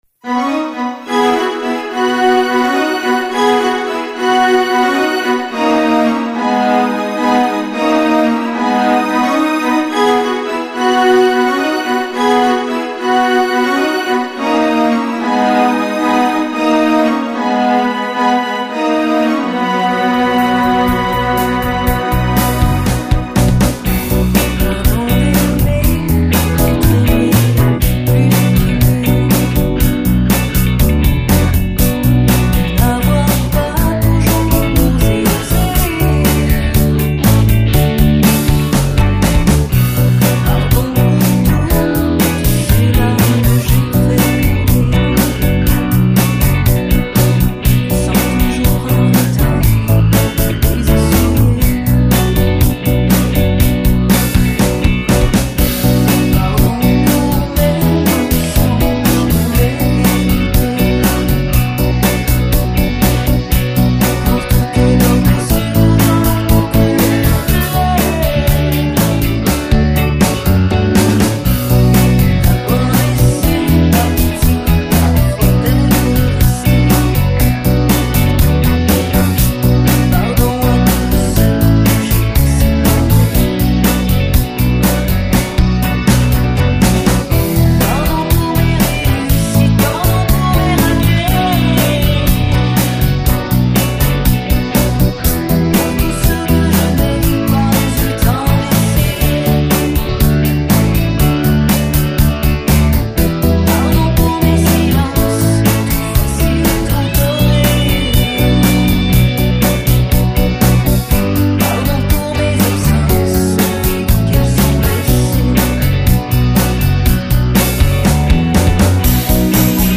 Les guitares sont quand meme bien en retrait, c'est dommage pour moi qui suis guitariste :((( .
Le petit changement de tonalite est bienvenu, la voix est un peu monotone dans le sens ou elle reste tout le temps dans le meme registre, et puis le synthe a la fin a sans doute un son un peu lourd (poisseux) par rapport a la chanson qui est legere (sucree), sinon dans le genre ca le fait.
Hmmm, y'aurait y pas un abus du phaser ?
Mais son très bon, la compo, pop à souhait. Efficace dans le style... peut-être un peu trop d'instruments, mais j'écoute au casque...